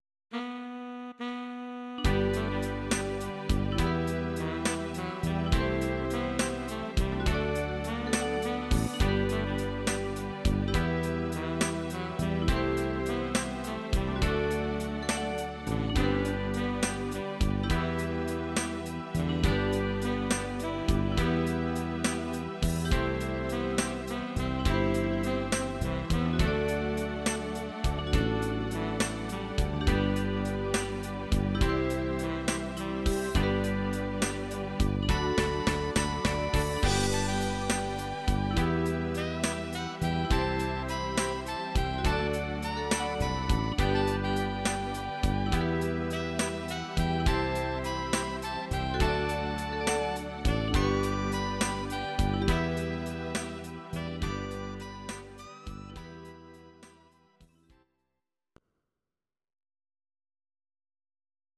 Audio Recordings based on Midi-files
Pop, Oldies, Duets, 1960s